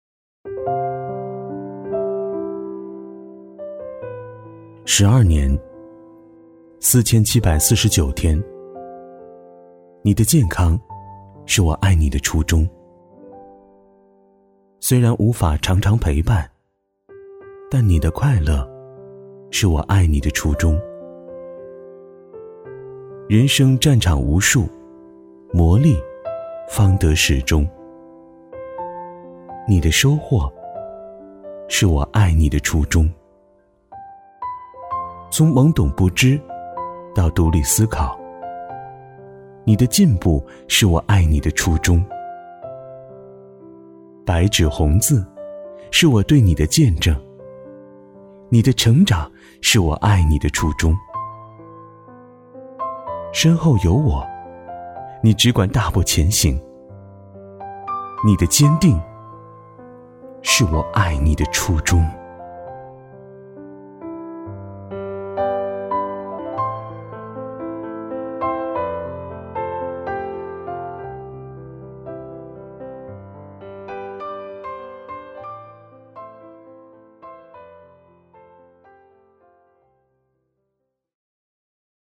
100%人工配，价格公道，配音业务欢迎联系：
B男177号
【旁白】讲述爱你的初衷